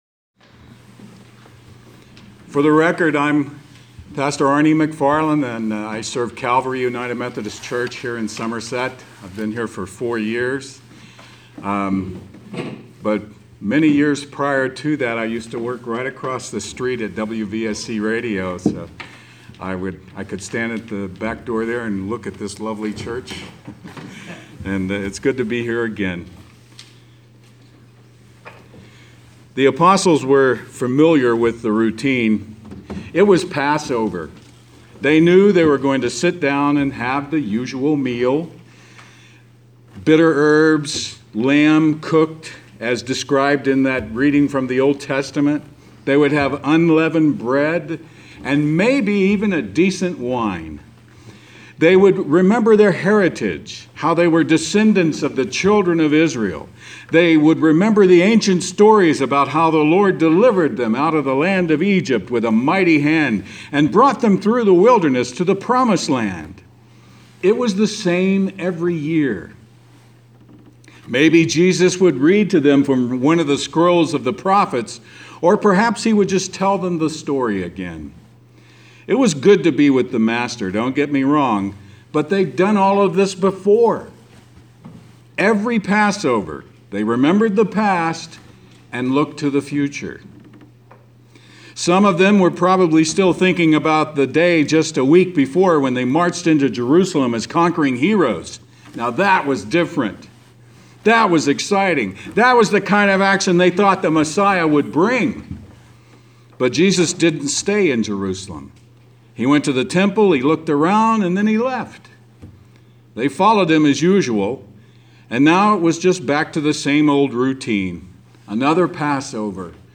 Last night on Maundy Thursday, I led our service Holy Communion while he preached.